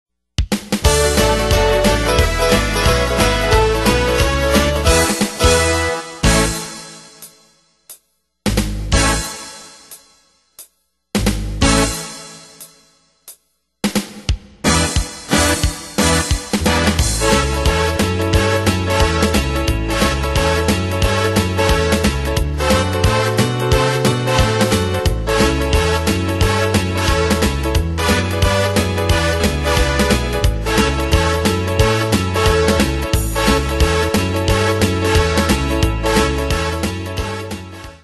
Danse/Dance: RockNRoll Cat Id.
Pro Backing Tracks